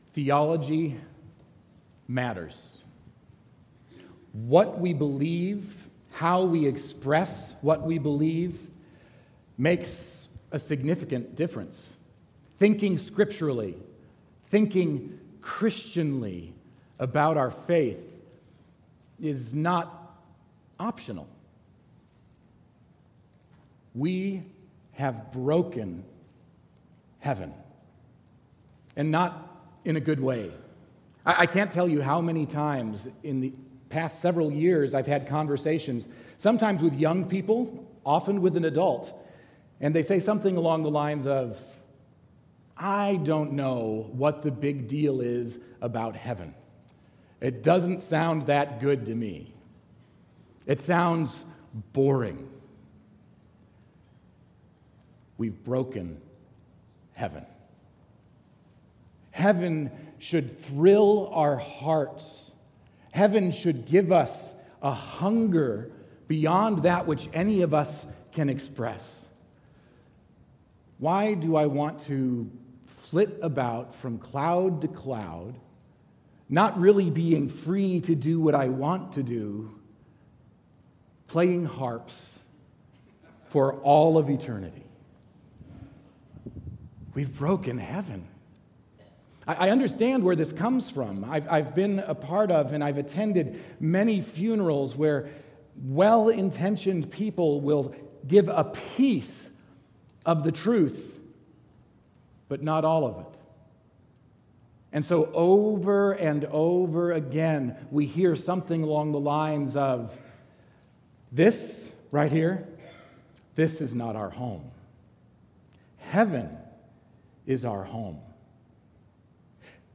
Sermon6.2.19.mp3